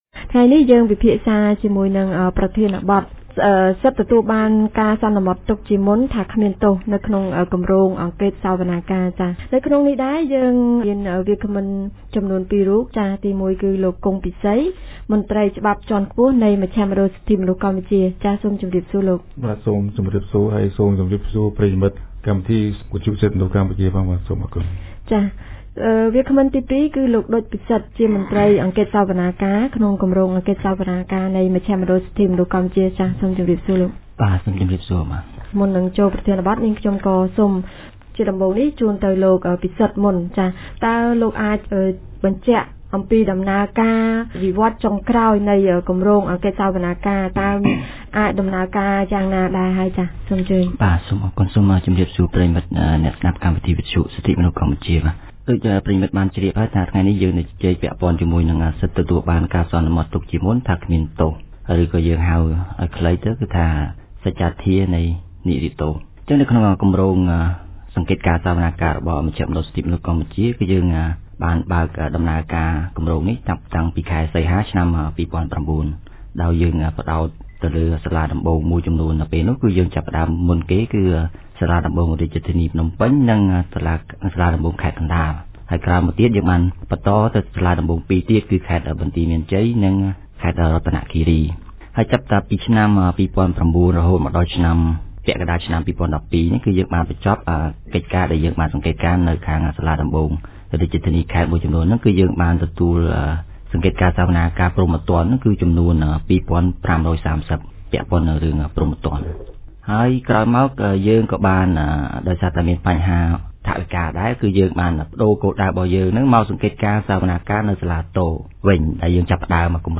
This TMP radio talk show debates on the issue of presumption of Innocence, which is a fundamental principle and a right of all accused to be presumed innocent until proven guilty and convicted in accordance with the law and by an impartial and competent tribunal. This fair trial right is guaranteed under Article 30 of the Cambodian Constitution and Article 12 of the International Convention on Civil and Political Rights.